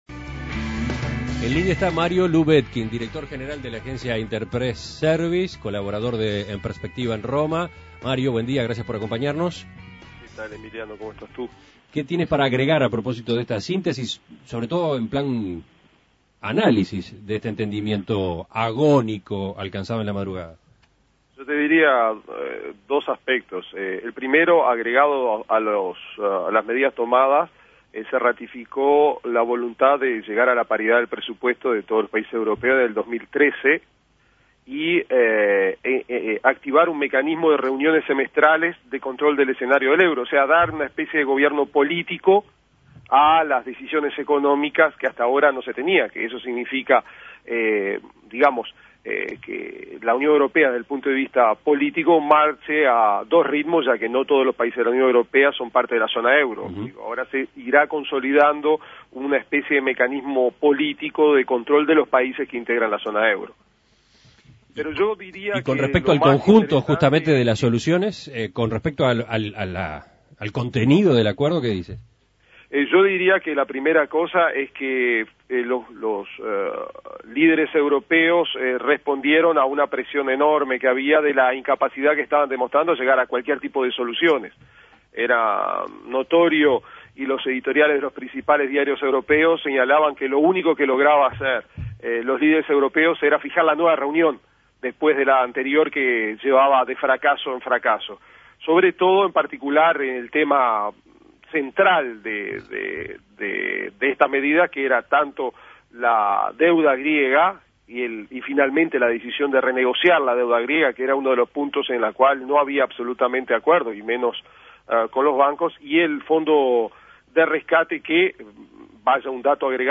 Escuche el contacto con Mario Lubetkin, colaborador de En Perspectiva desde Roma.